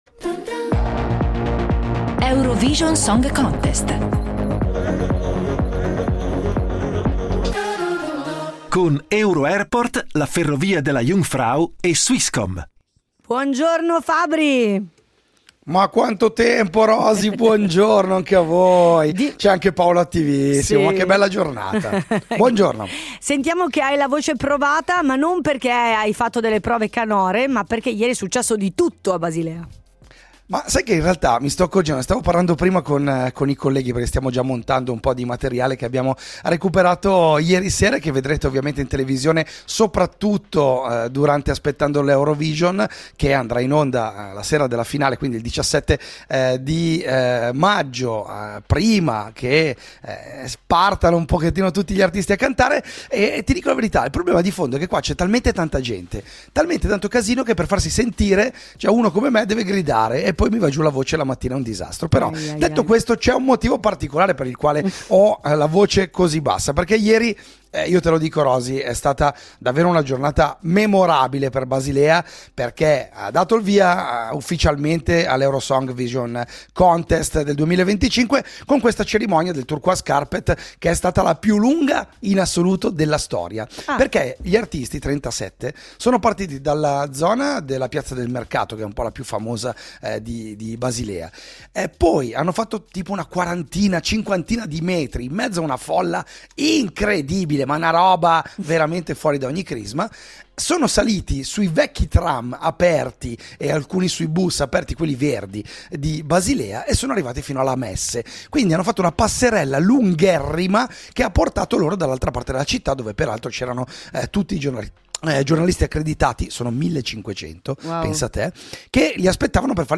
Eurovision ‘25: in collegamento da Basilea